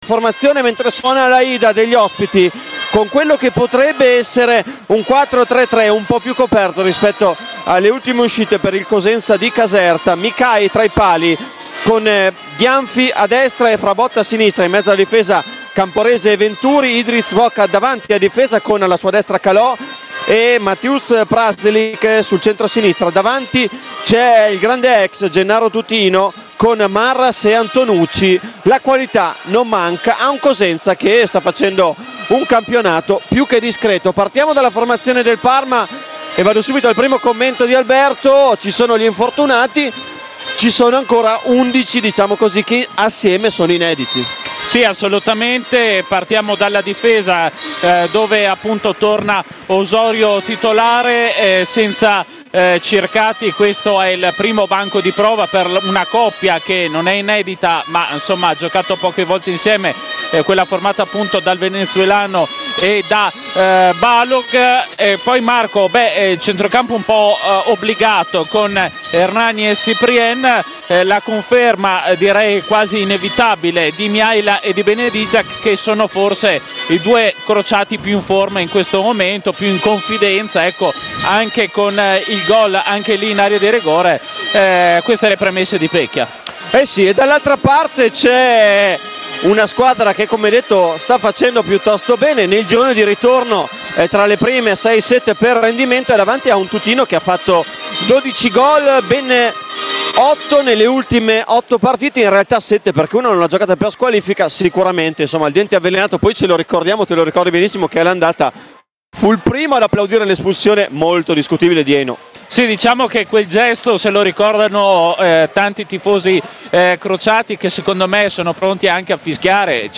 Senza Sohm e Bernabè infortunati, con Estevez squalificato, Pecchia lascia in panchina Delprato e Circati e, in avanti, punta sulle quattro “punte” con Man, Mihaila, Partipilo e Benedyczak in campo insieme. Radiocronaca
Commento tecnico